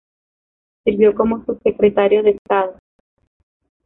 Pronunciat com a (IPA)
/ˈkomo/